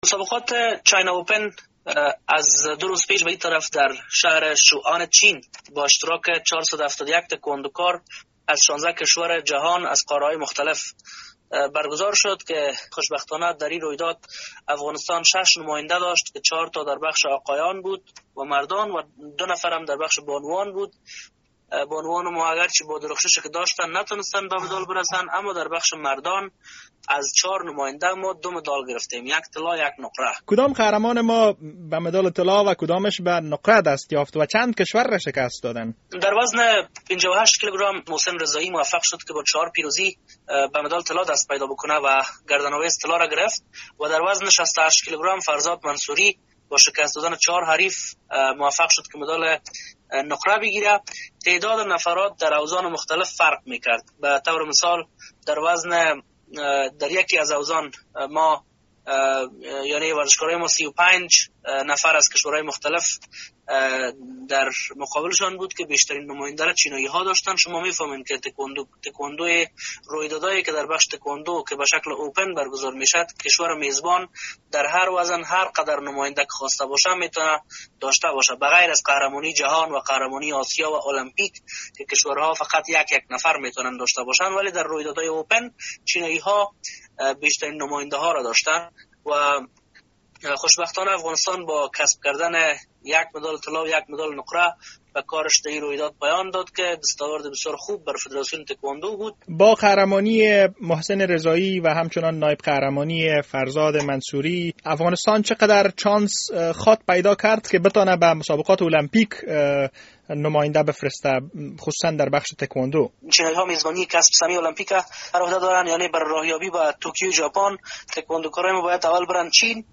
مصاحبه‌های ورزشی